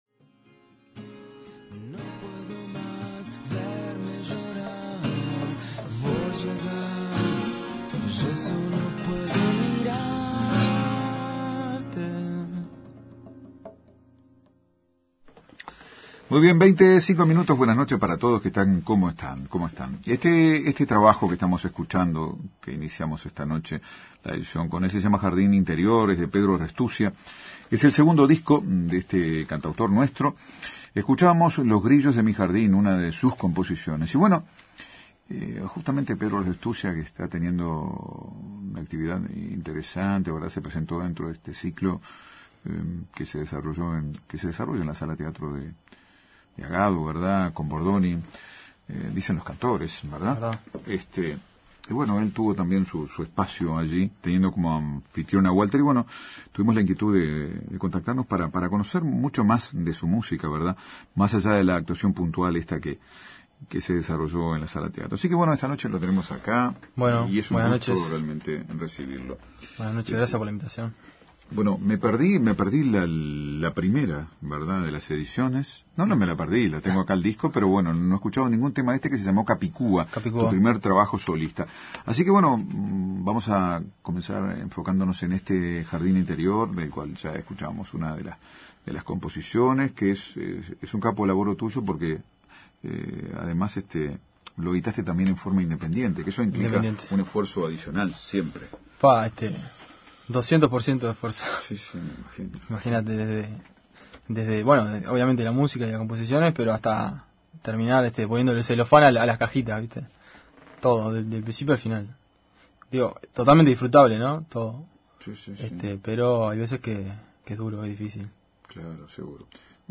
Entrevista radial programa Entre El Micrófono y la Penumbra.